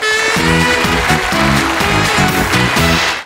schweinchen.wav